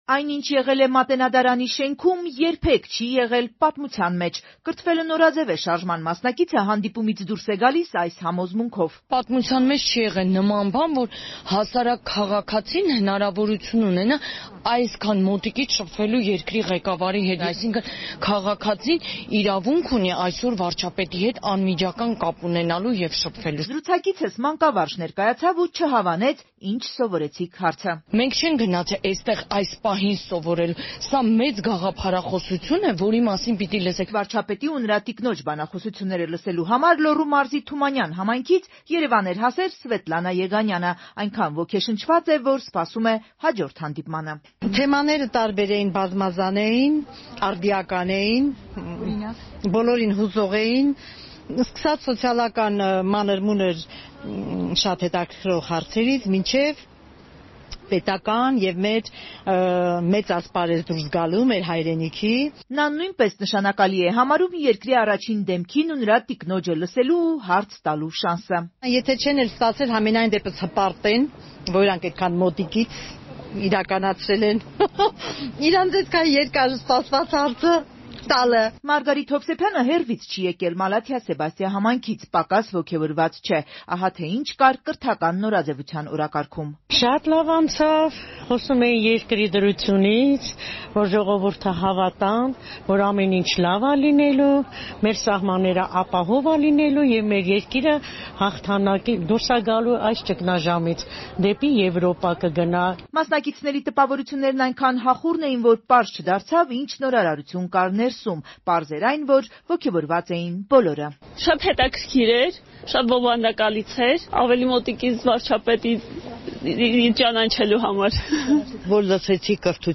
Ռեպորտաժներ
«Սոցիալական մանր-մունր հարցերից մինչև մեծ ասպարեզ». «Կրթվելը նորաձև է» շարժման հանդիպումը Մատենադարանում